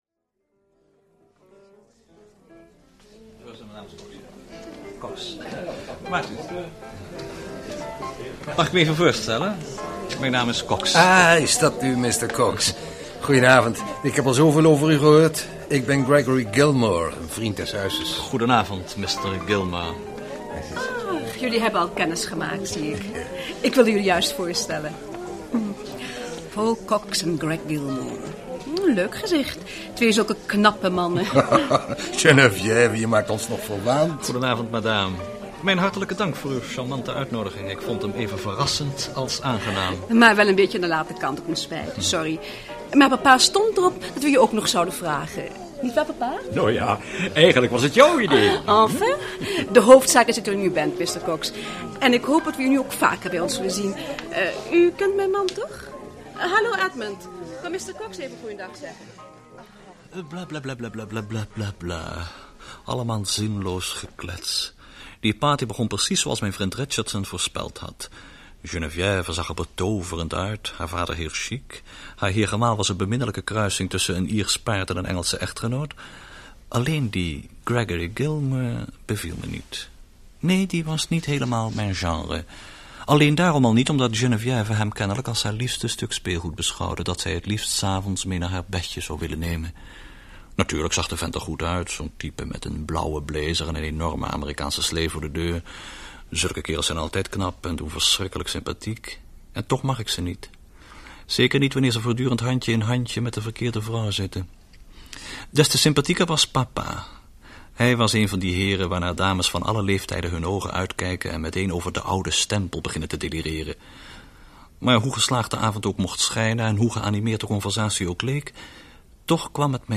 Uitgeverij Rubinstein heeft voor deze zomer (2011) gekozen voor de hoorspelserie met de lange titel “Mag ik mij even voorstellen? Mijn naam is Cox!”
Oorspronkelijk werd dit hoorspel in 1968 uitgezonden en in 1977 herhaald.